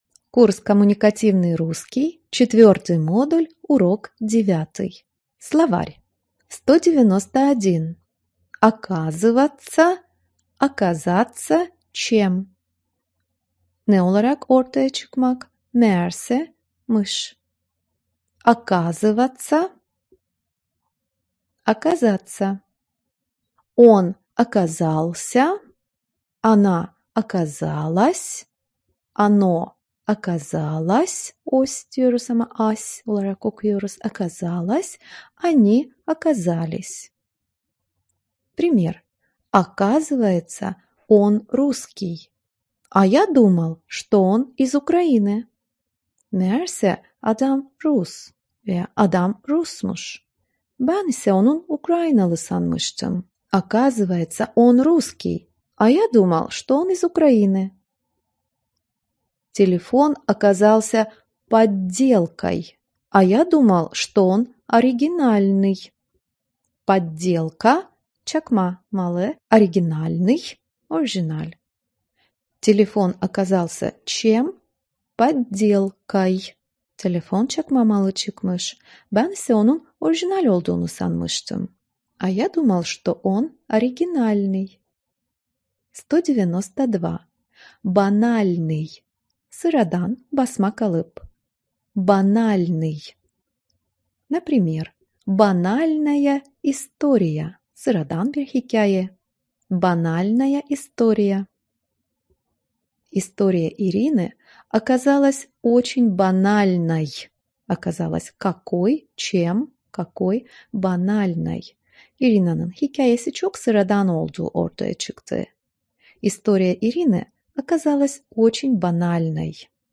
Online Video Dersleri
Eğitimcinin, kullanıcı ile yüz yüze ders anlattığı ve derse ait bilgileri sunum ve interaktif grafiklerle pekiştirmeyi amaçladığı halde  hazırlanan video ders  içeriklerini kullanıcı kendine uygun zamanlarda isterse tek parça halinde isterse parça parça izleyerek özgürce Rusça eğitimi almaktadır.